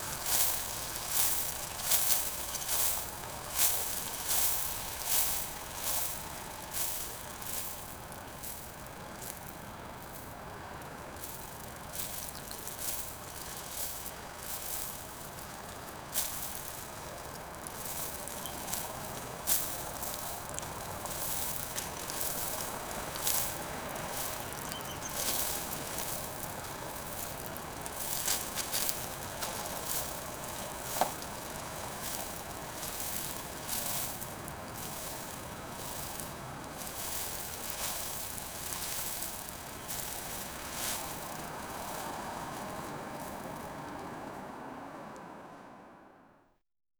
Royalty-free grasslands sound effects
grasslands-with-a-old-fas-pvv7sder.wav